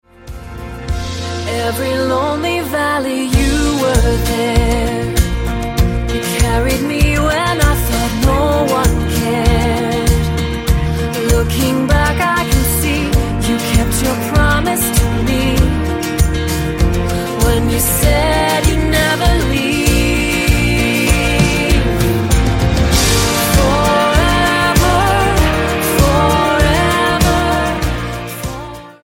Style: MOR/Soft Pop Approach: Praise & Worship